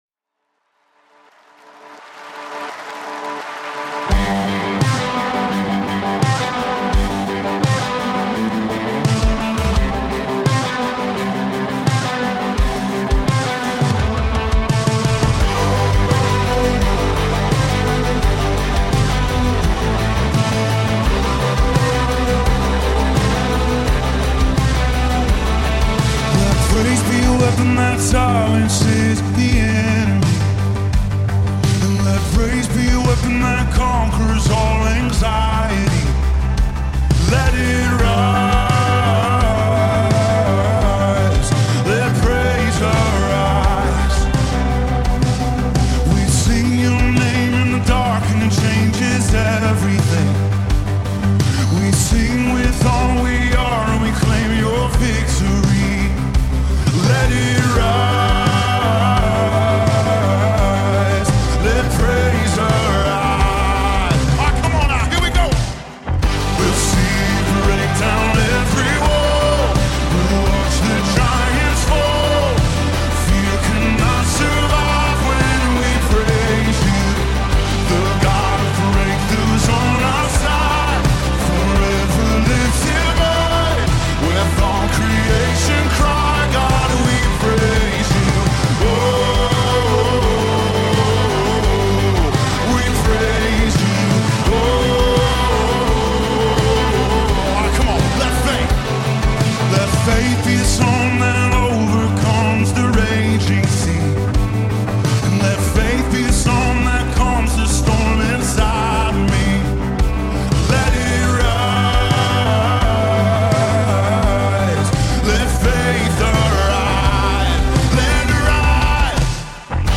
MP3 TRANSPOSED KEY for audition